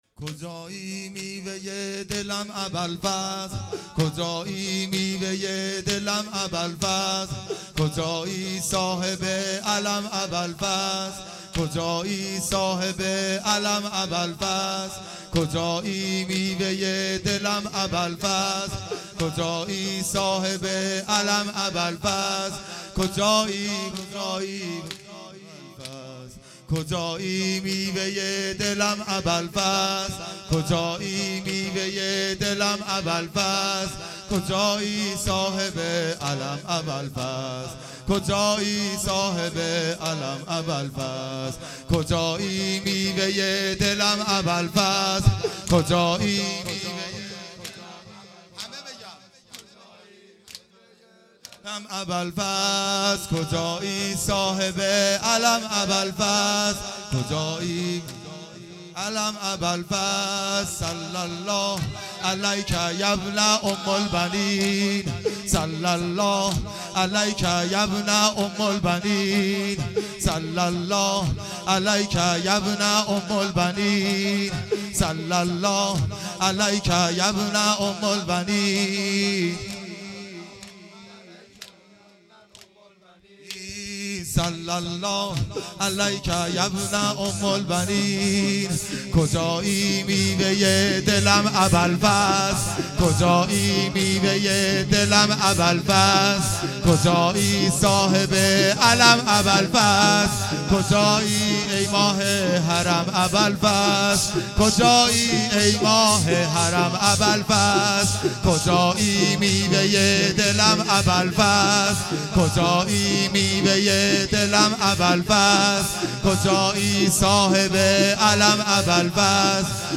گلچین مراسمات اسفندماه